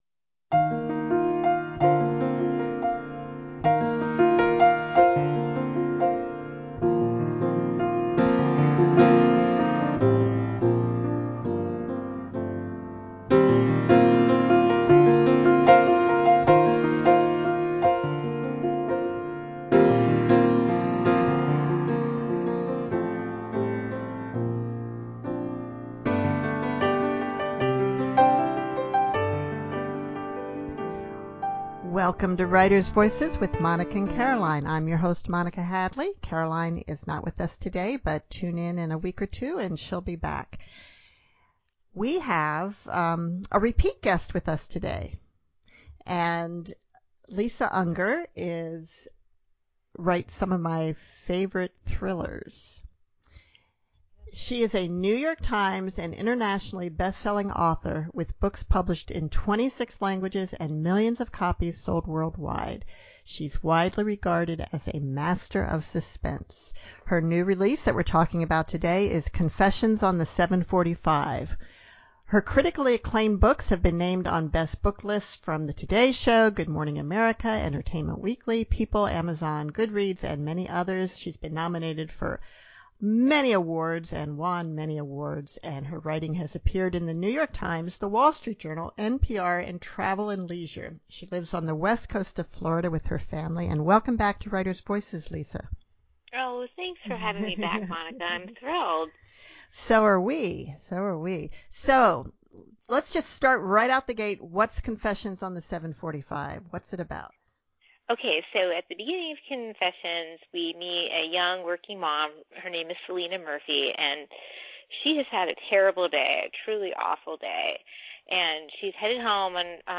In this conversation we talk a lot about process and perspective, and of course her new book (#18 if you’re counting) – “Confessions on the 7:45.”